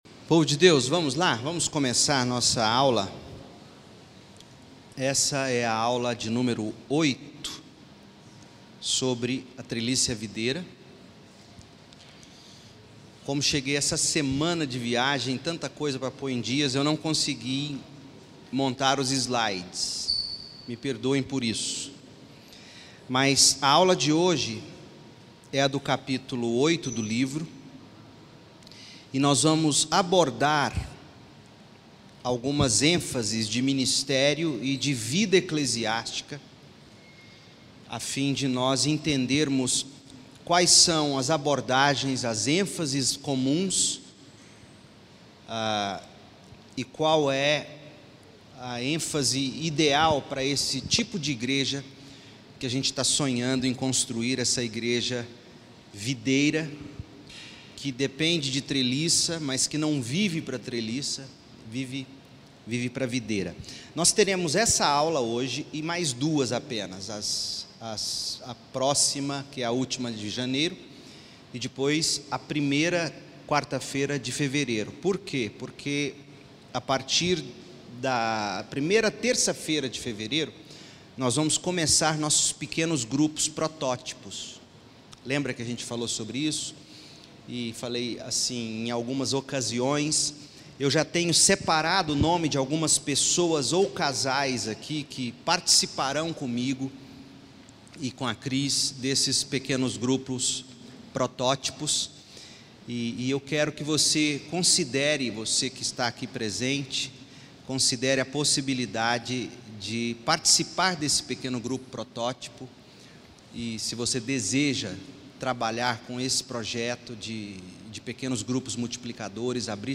Aula 8 do curso a treliça e a videira.